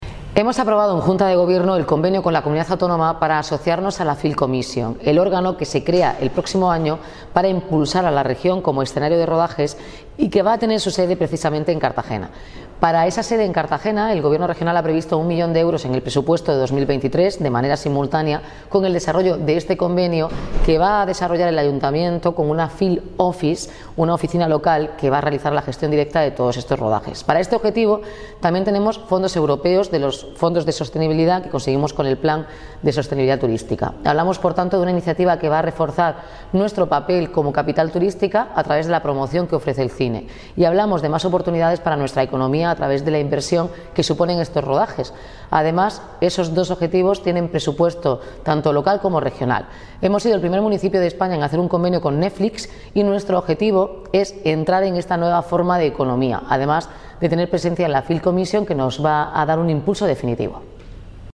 Enlace a Declaraciones de Noelia Arroyo sobre la Film Commission